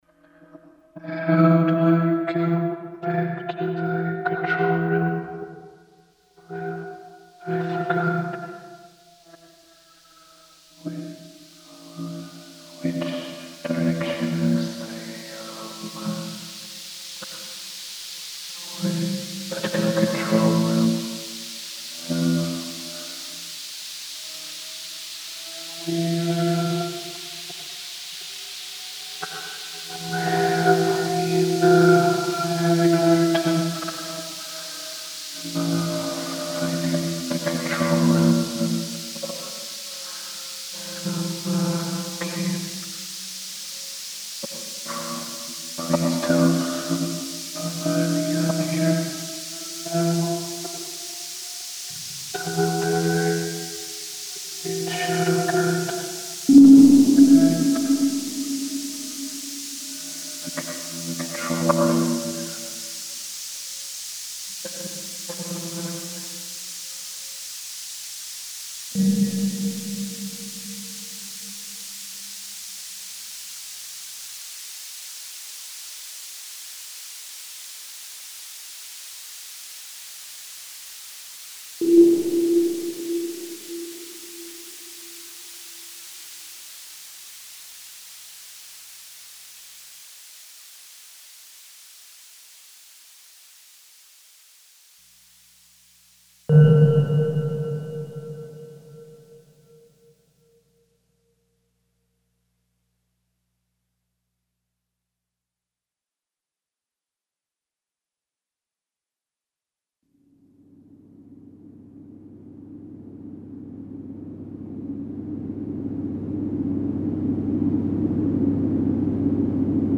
Stereo mixes of 4 channel sound installation in Shadowgut.